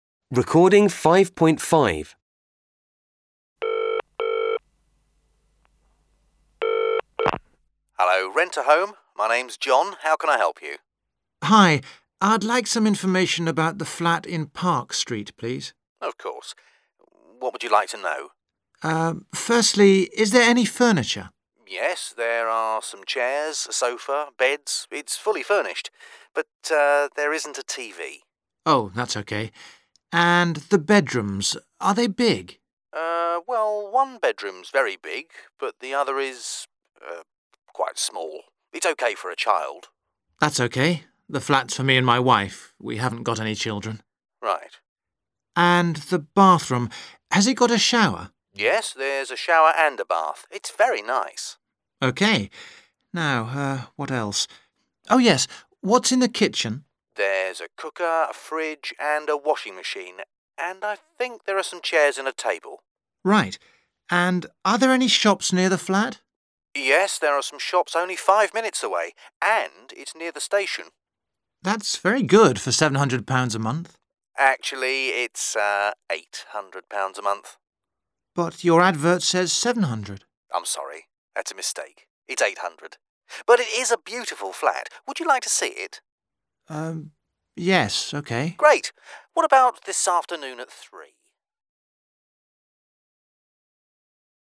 Listening Part 3 Listen to a telephone conversation